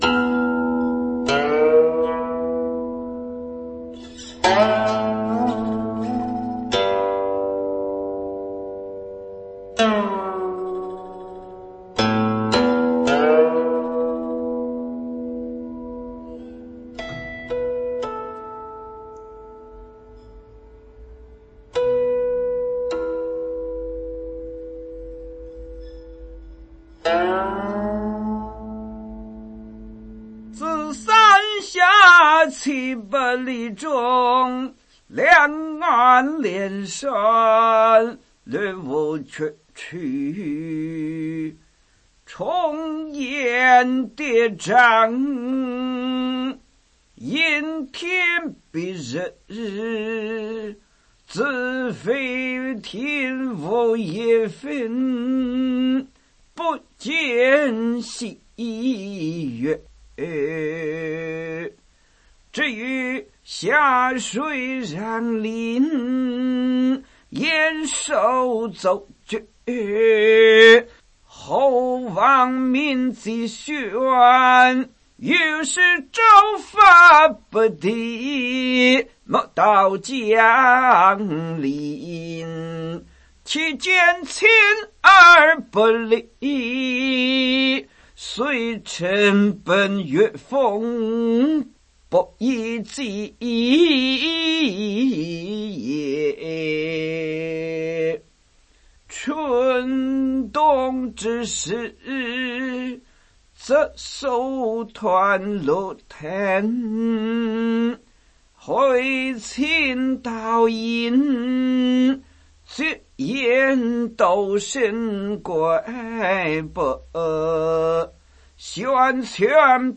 誦唸